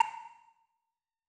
Sound / Effects / UI / Retro5.wav